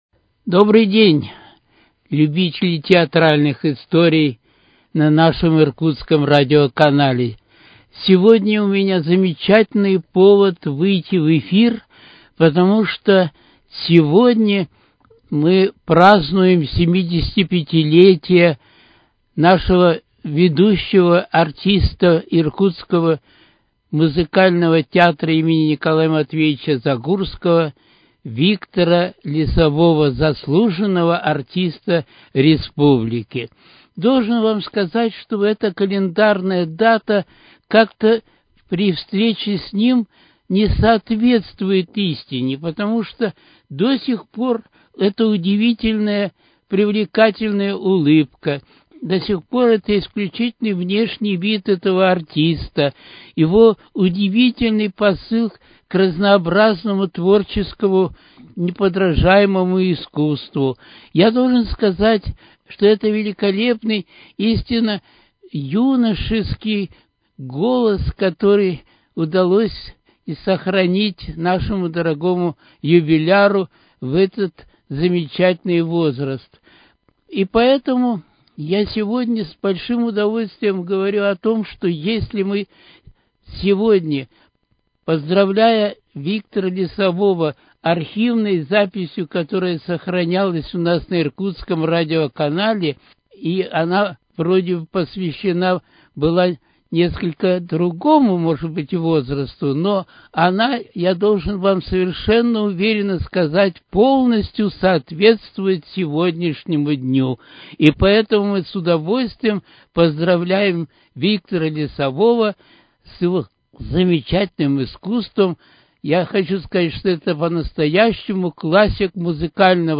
Театральные истории: Беседа
Запись 2019 года.